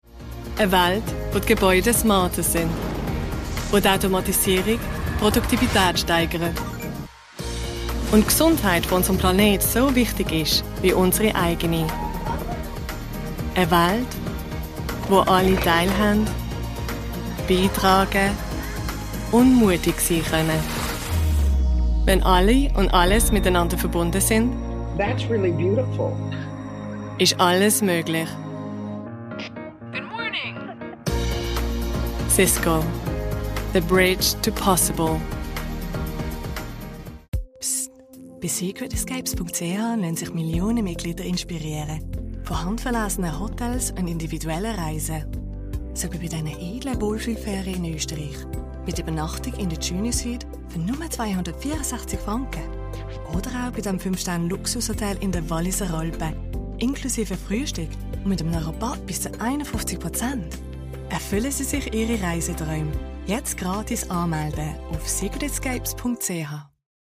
German, Swiss-German, Female, Home Studio, 20s-30s